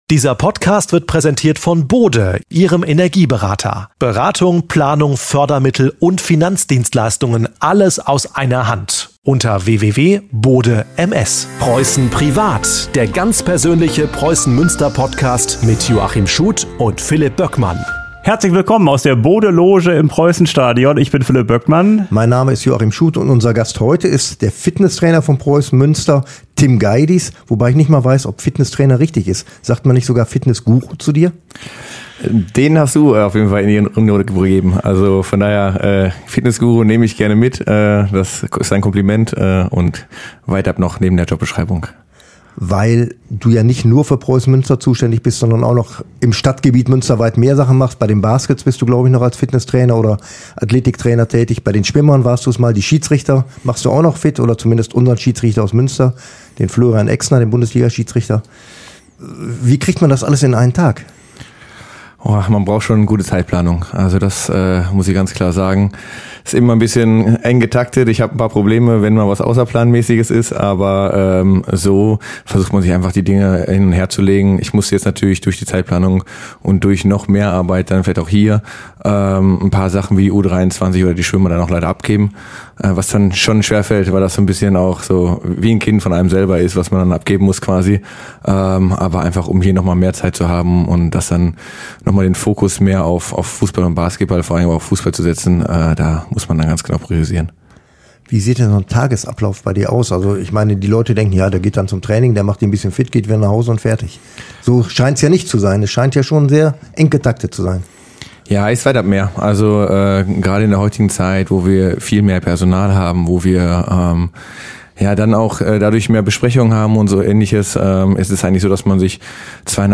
Ein ehrliches Gespräch über Berufung und Teamgeist.